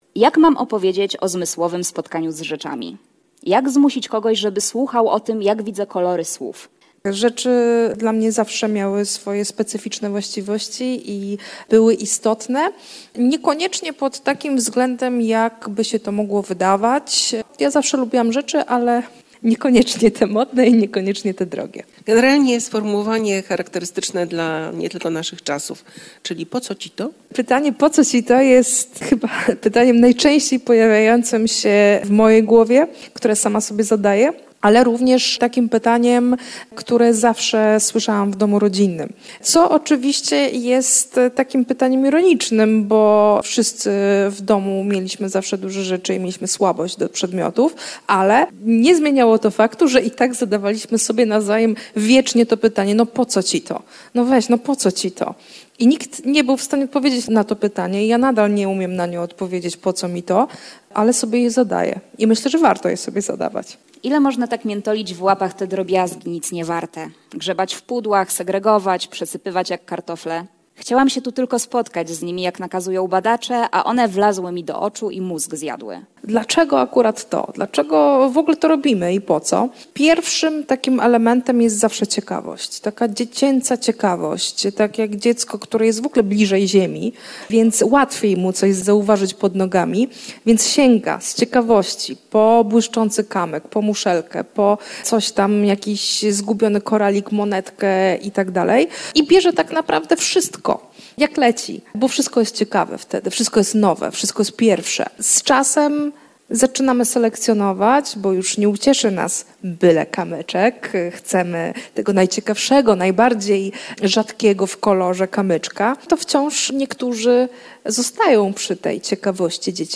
w ramach „Bitwy o literaturę” w Teatrze Starym w Lublinie